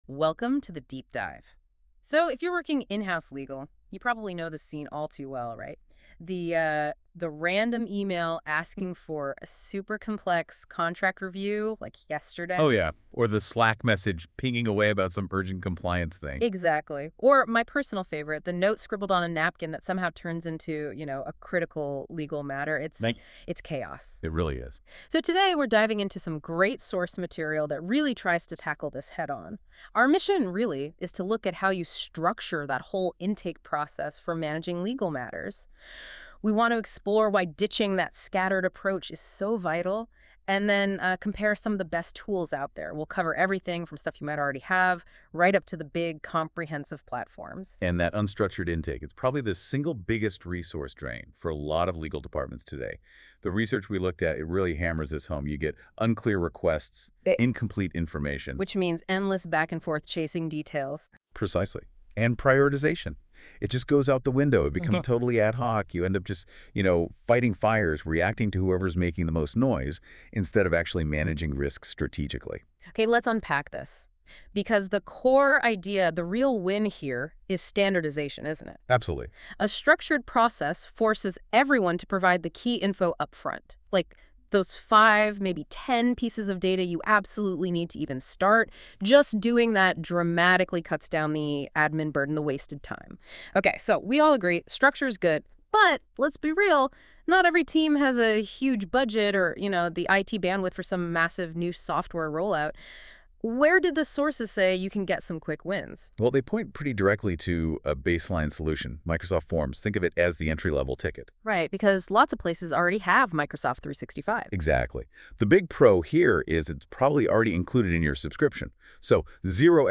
Google Notebook LM - Intake Options for Legal Matter Management.m4a